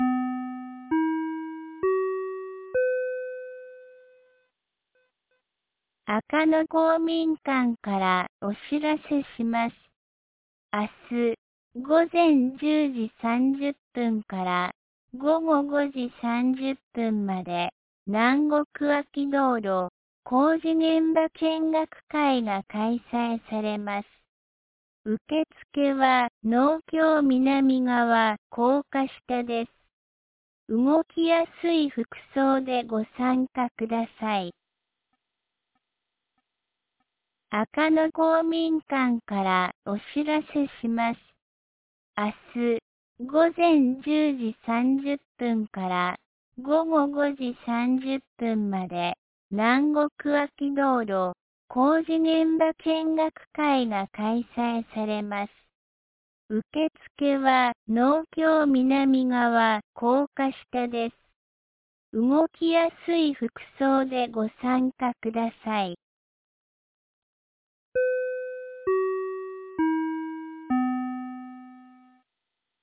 2025年04月17日 12時56分に、安芸市より赤野へ放送がありました。